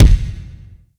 drum-hitnormal3.wav